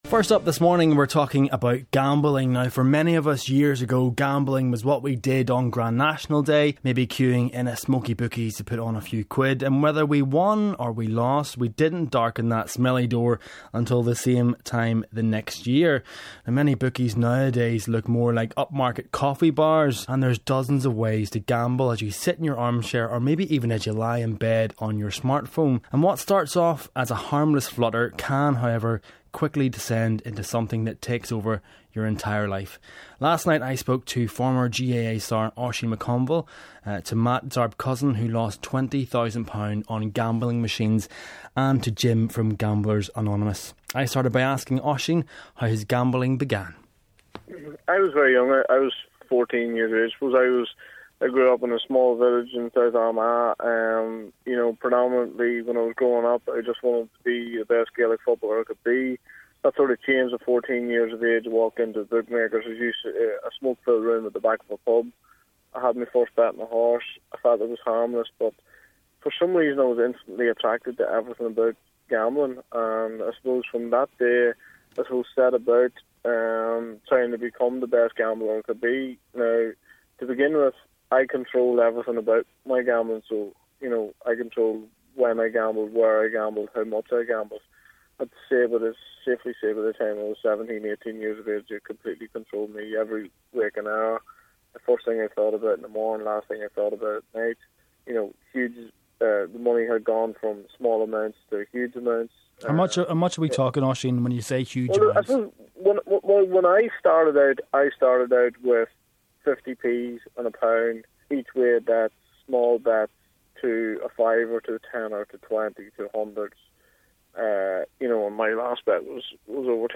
The destructive grip of problem gambling – recovering addicts tell their stories